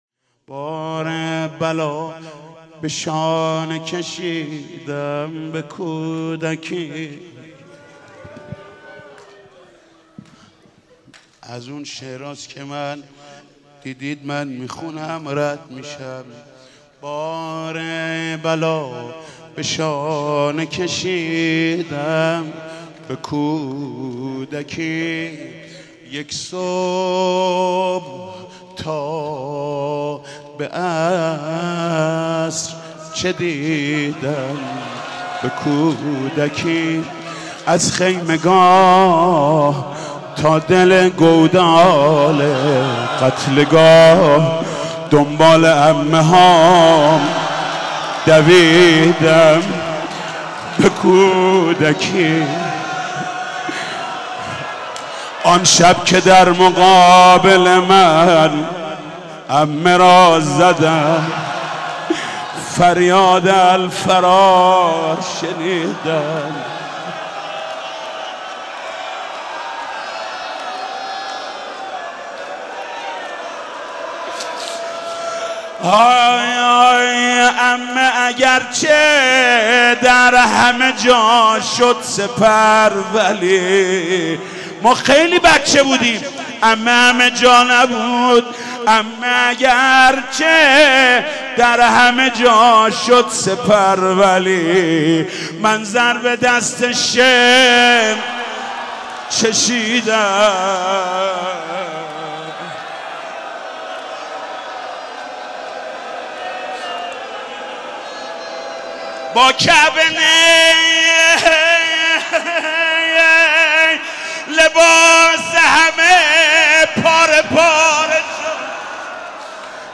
شهادت حضرت امام محمد باقر علیه السلام 1393 | مسجد حضرت امیر | حاج محمود کریمی
بار بلا به شانه کشیدم به کودکی | روضه | حضرت امام محمد باقر علیه السلام